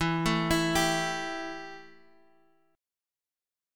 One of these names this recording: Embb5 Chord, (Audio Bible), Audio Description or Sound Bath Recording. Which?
Embb5 Chord